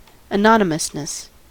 anonymousness: Wikimedia Commons US English Pronunciations
En-us-anonymousness.WAV